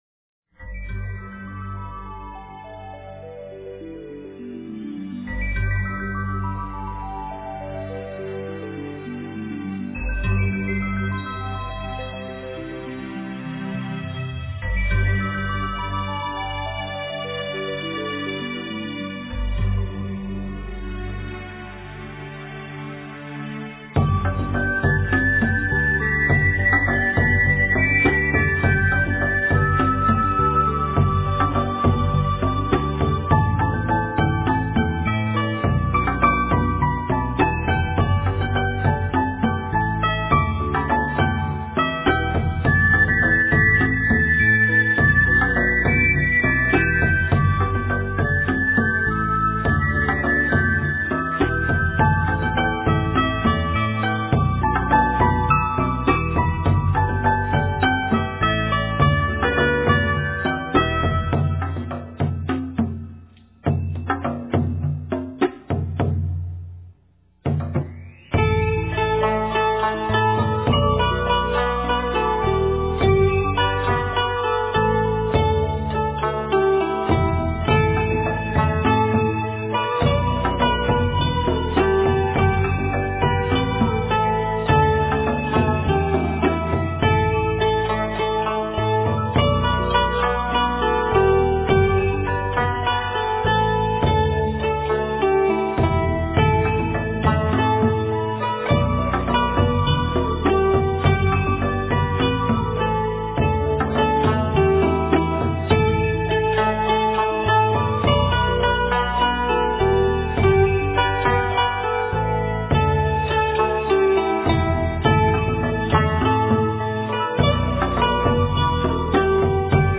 标签: 佛音冥想佛教音乐